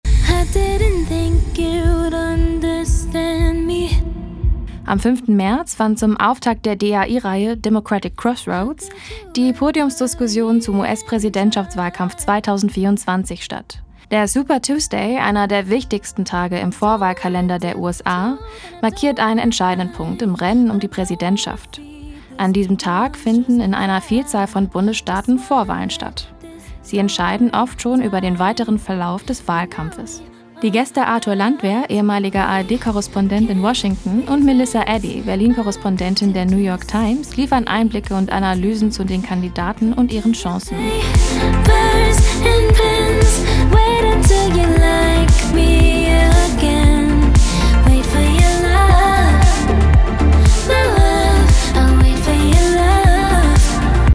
„Democratic Crossroads – Eine Gesprächsreihe zur US-Wahl“ mit dem Hybrid-Podium in gekürzter Fassung zeigt, wie sich der Wahlkampf weiter entwickeln kann.
Teaser_549.mp3